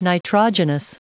Prononciation du mot nitrogenous en anglais (fichier audio)
Prononciation du mot : nitrogenous